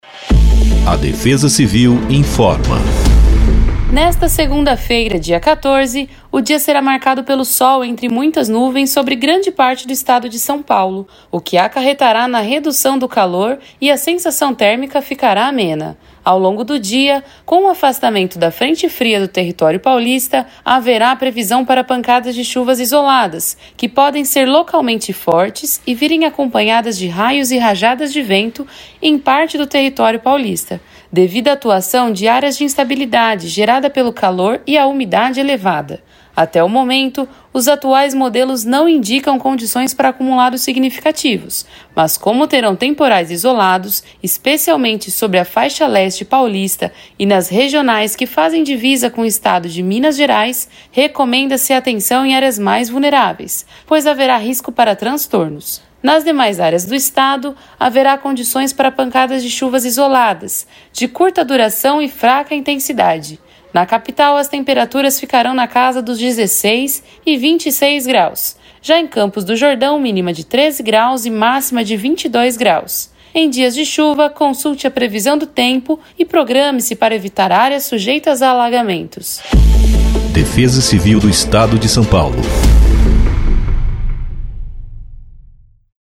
Defesa-Civil-Boletim-Previsao-do-Tempo-para-1404-Spot.mp3